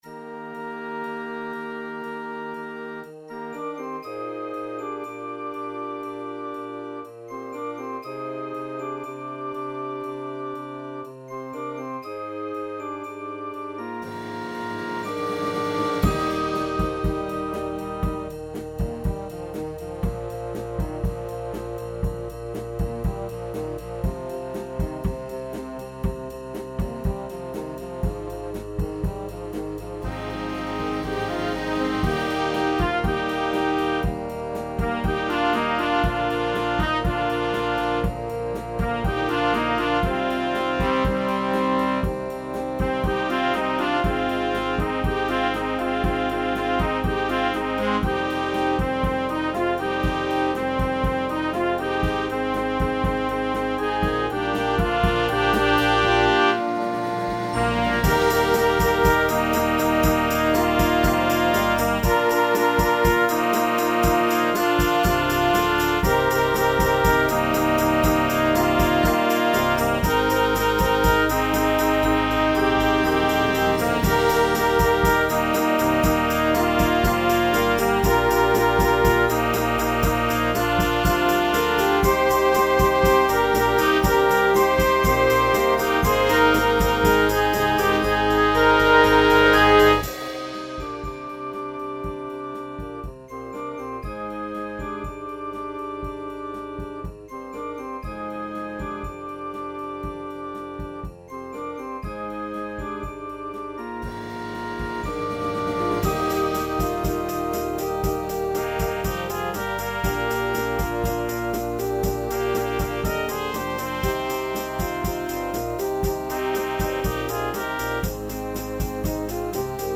pop, rock, instructional, children